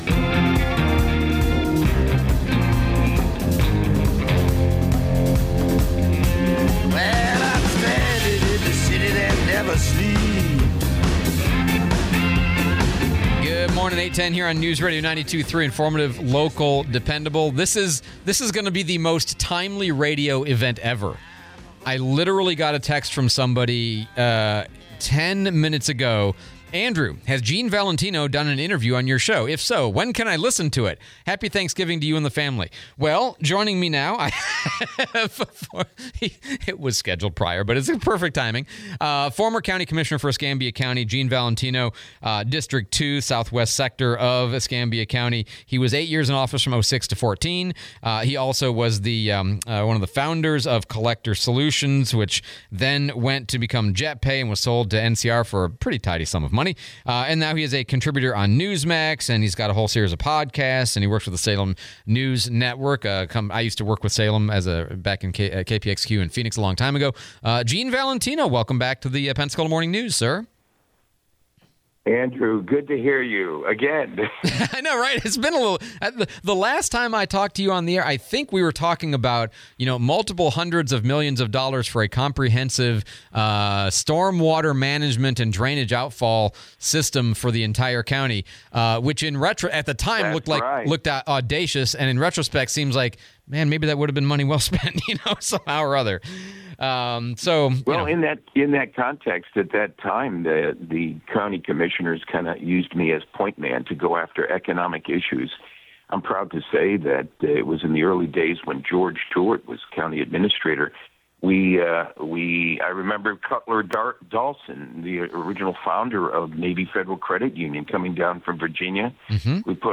11/26/24 Gene Valentino Interview